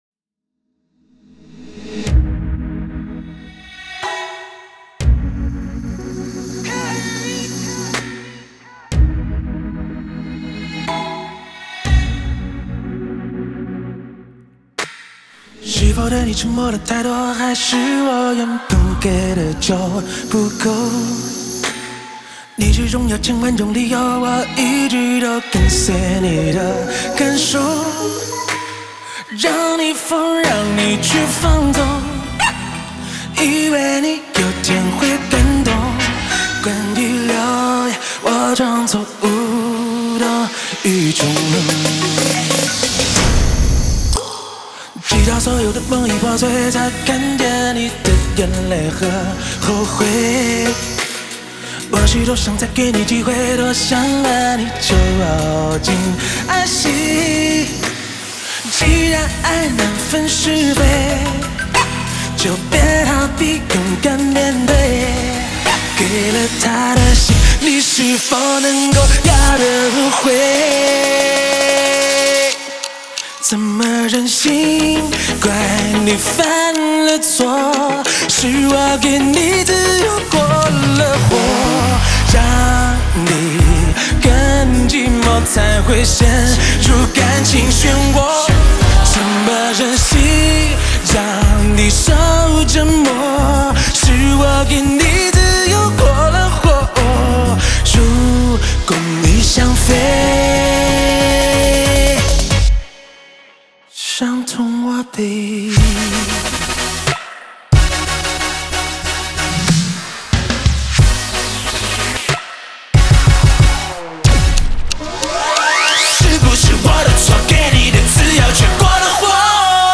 Ps：在线试听为压缩音质节选，体验无损音质请下载完整版
Live